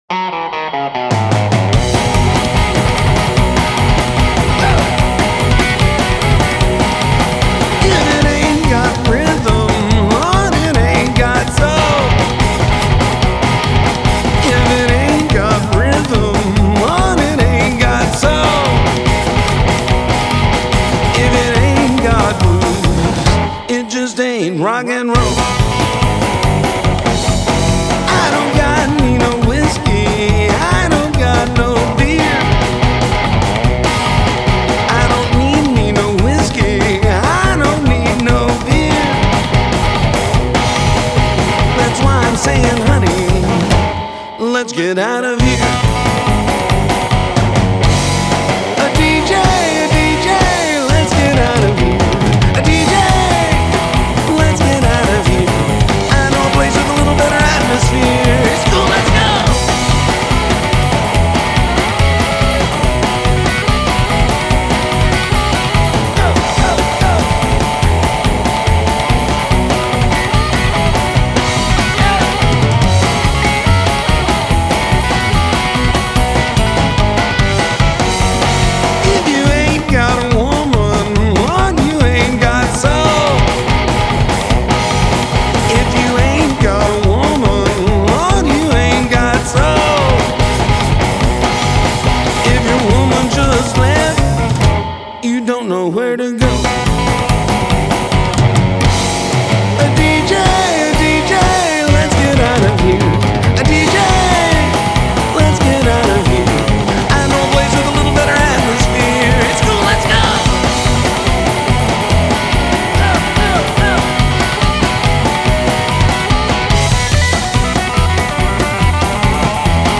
If you're looking for some red-hot Rockabilly